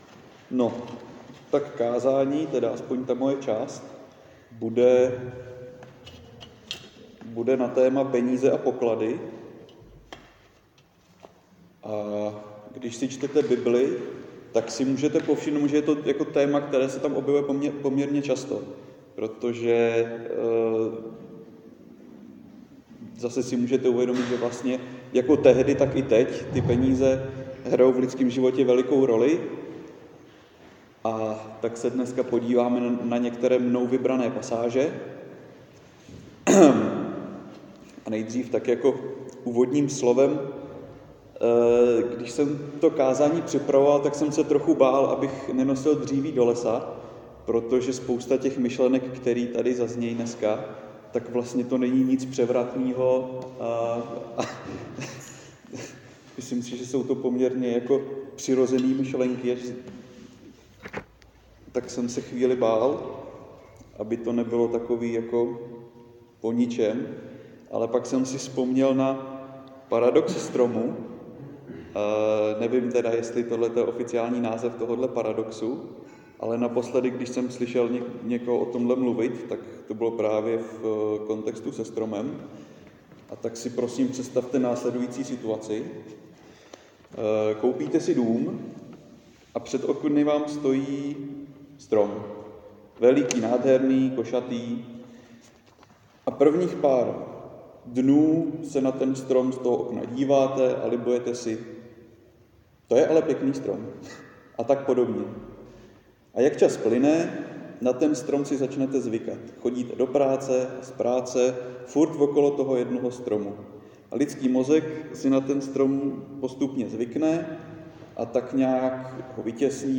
Křesťanské společenství Jičín - Kázání 17.11.2025